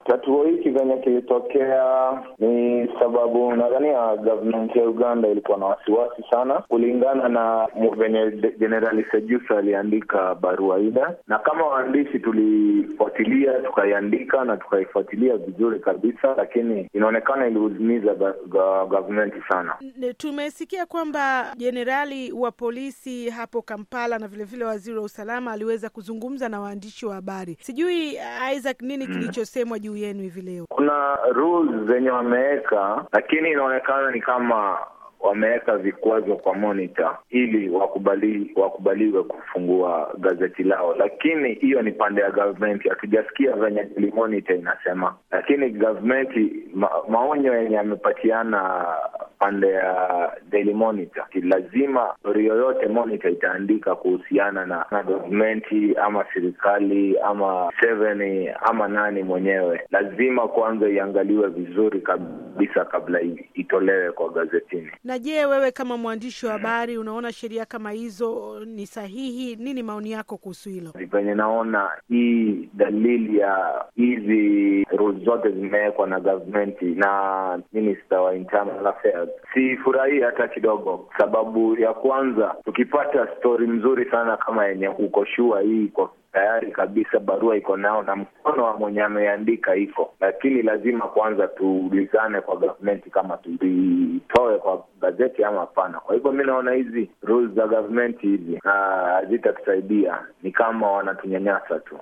Mahojiano na mwandishi wa Monitor - 1:42